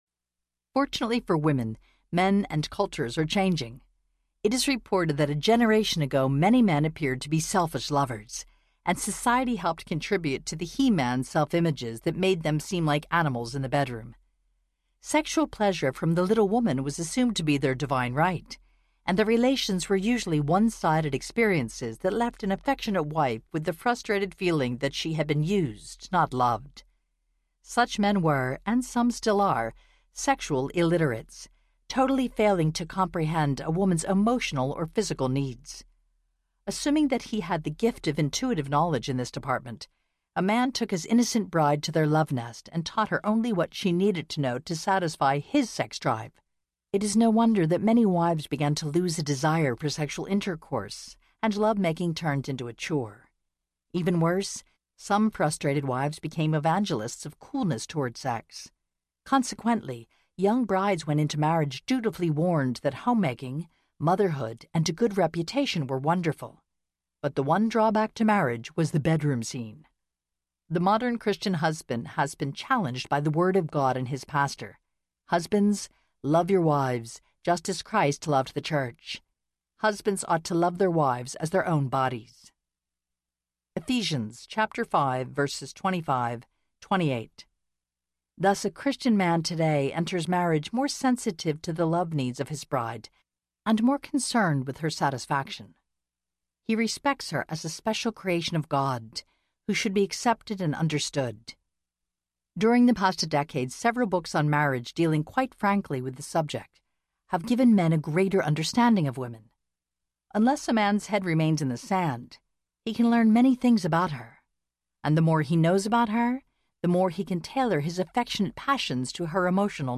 The Act of Marriage Audiobook
Narrator
10.0 Hrs. – Unabridged